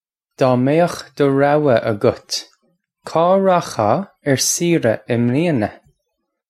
Pronunciation for how to say
Daw may-ukh duh rao-wa uggut, kaw rakh-haw urr seera im lee-unna
This is an approximate phonetic pronunciation of the phrase.